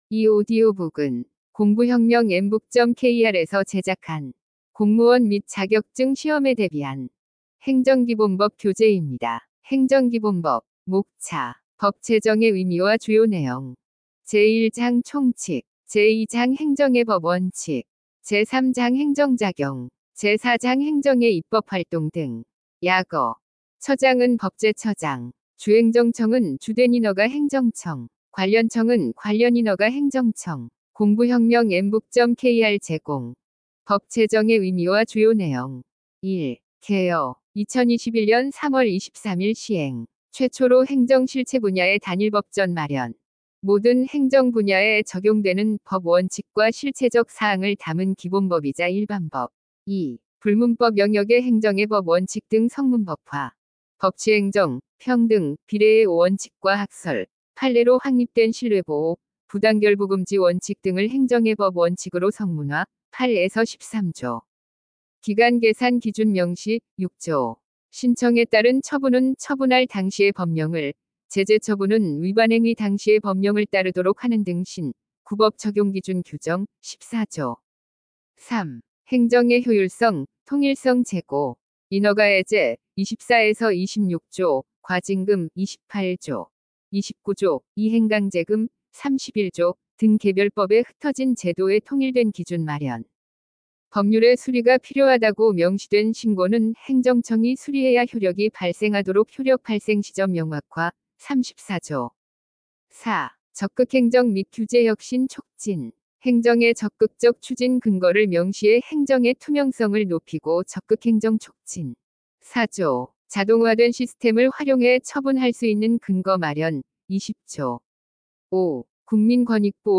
– 인공지능 성우 이용 오디오 학습 교재
– 인공지능 성우가 강의